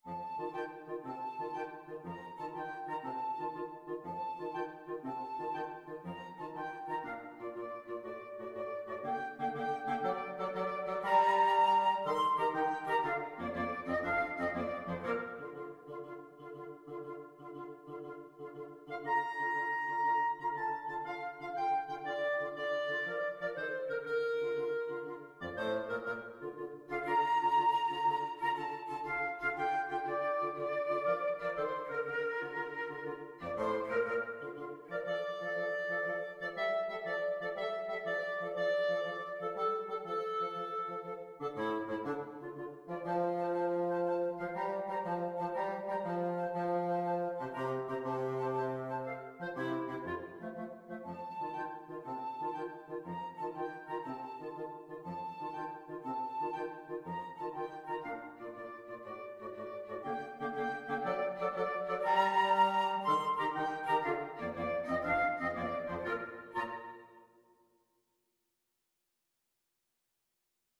Woodwind Trio version
FluteClarinetBassoon
6/8 (View more 6/8 Music)